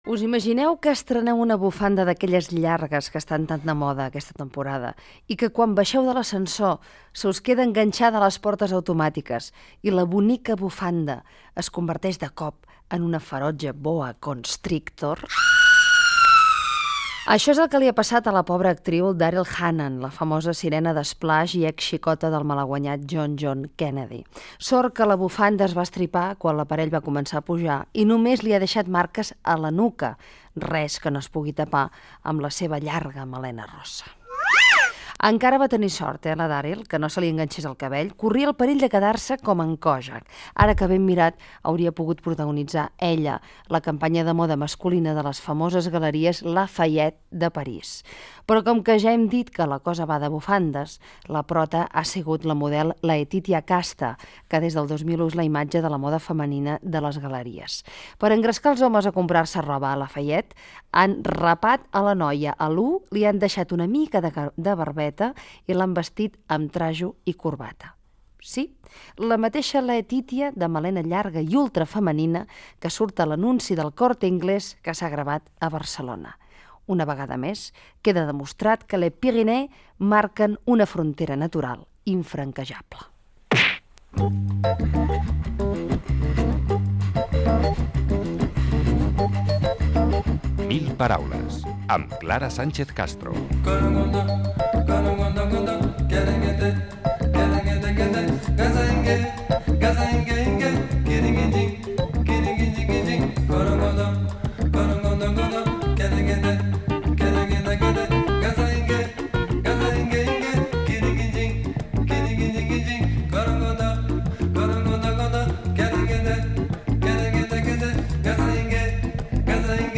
Comentari sobre la bufanda de l'actriu Daryl Hannah , careta del programa, hora, presentació, crèdits, els punts del carnet de conduir, sumari.
Entreteniment
FM